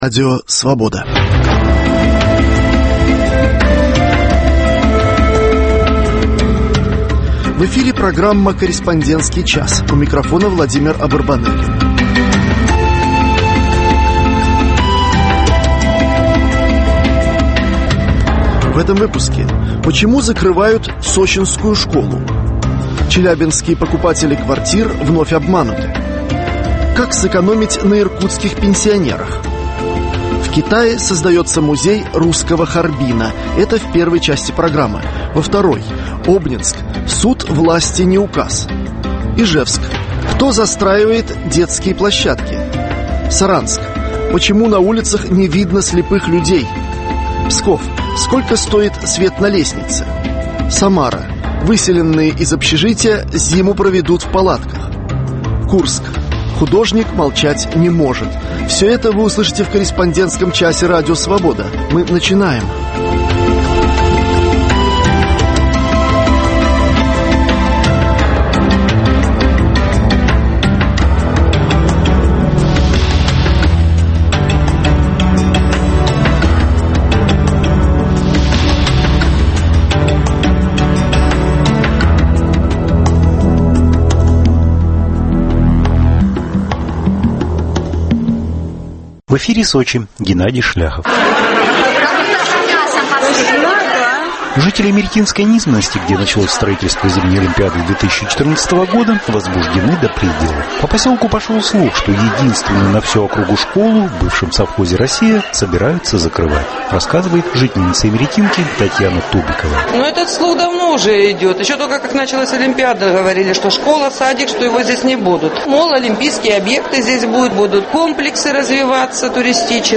Еженедельная серия радиоочерков о жизни российской провинции. Авторы из всех областей России рассказывают о проблемах повседневной жизни обычных людей.